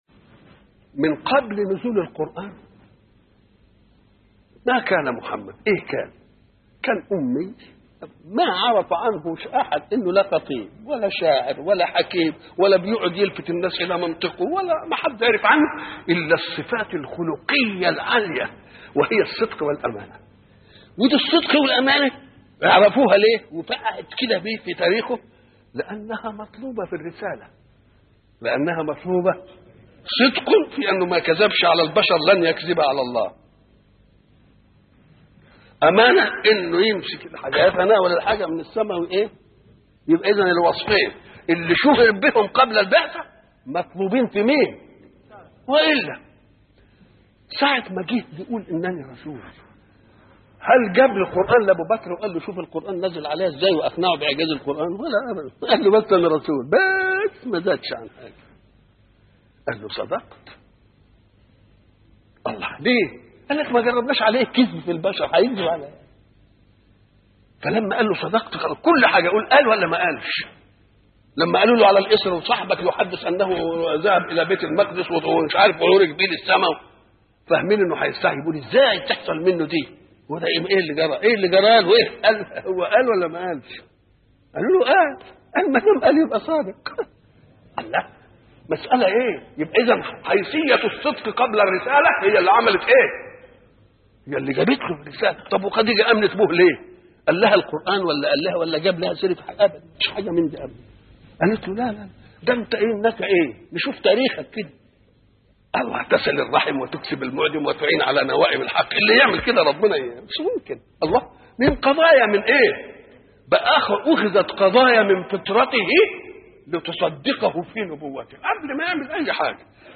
شبكة المعرفة الإسلامية | الدروس | الرسول قبل البعثة |محمد متولي الشعراوي